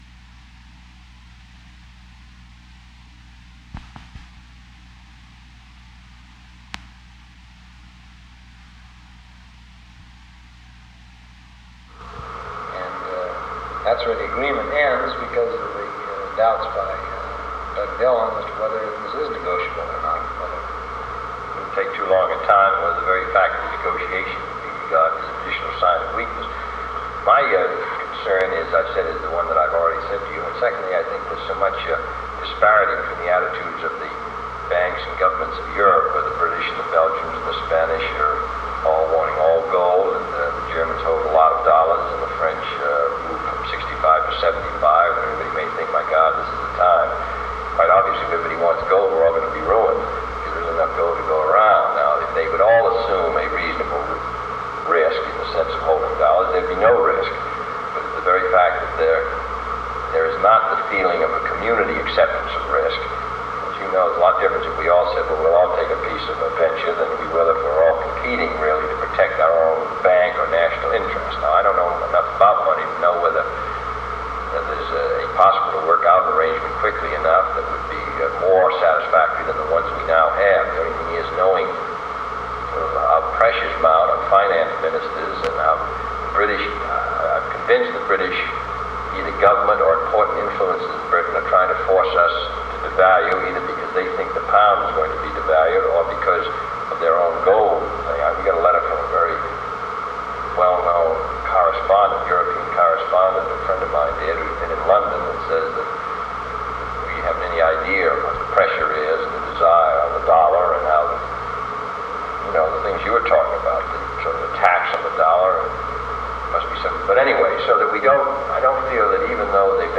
Secret White House Tapes | John F. Kennedy Presidency Meeting on the Gold and Dollar Crisis Rewind 10 seconds Play/Pause Fast-forward 10 seconds 0:00 Download audio Previous Meetings: Tape 121/A57.